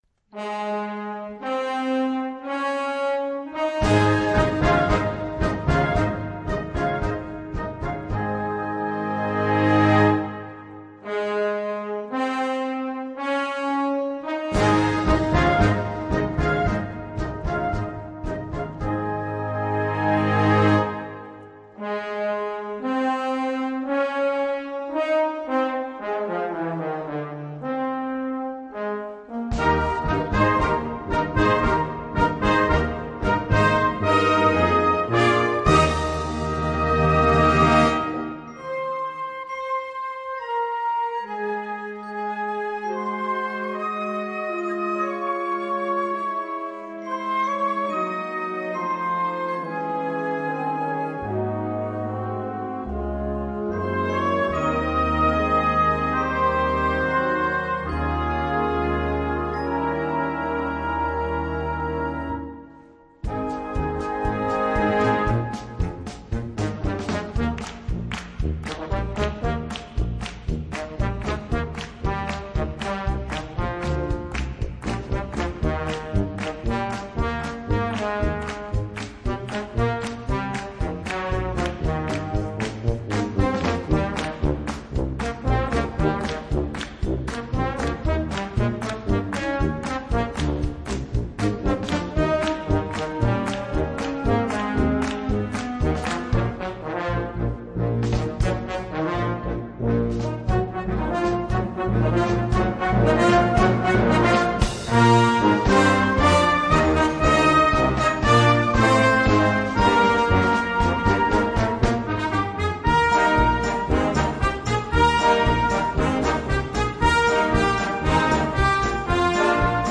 Gattung: für Jugendkapelle
Besetzung: Blasorchester